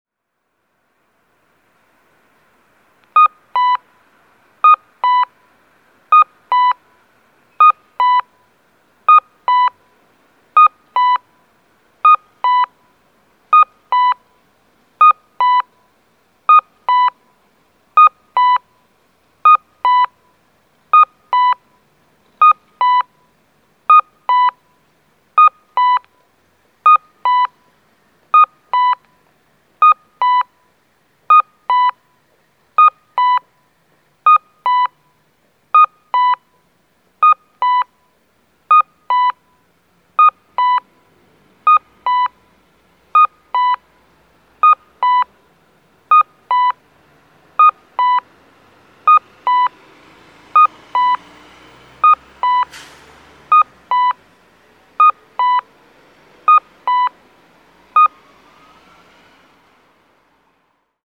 岩国駅前交差点(山口県岩国市)の音響信号を紹介しています。